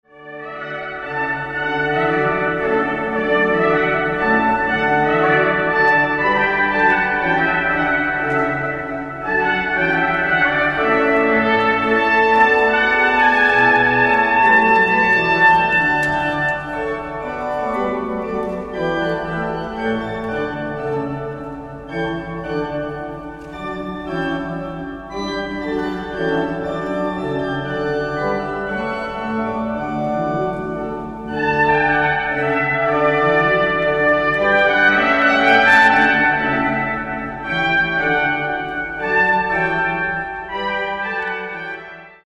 Trompete
Orgel